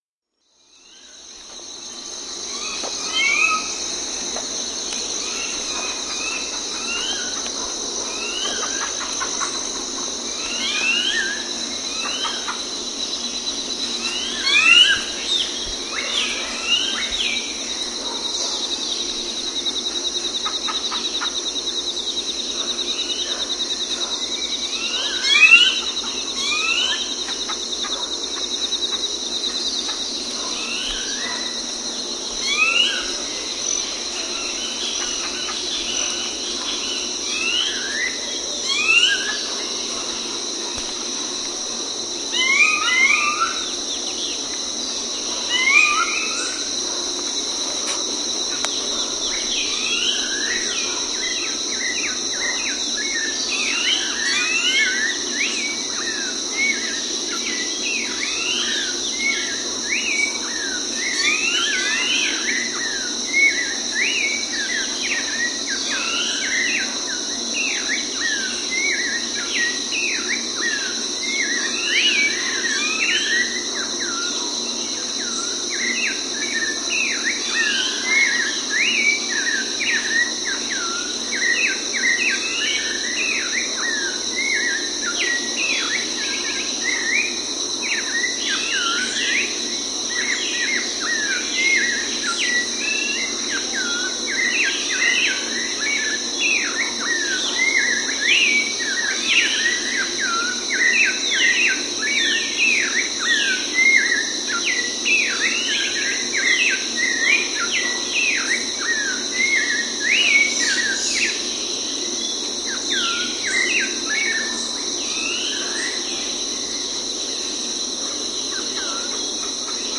尤卡坦岛 " 晨鸟
描述：在墨西哥尤卡坦州乌斯马尔市我们酒店的花园里，早上6点就有不同的鸟鸣和叫声。在背景中，有24小时的蝉和蟋蟀的合唱。我削减了低频，以摆脱背景中嘈杂的发电机。
Tag: 场记录 环境 鸟的歌声 雨林 热带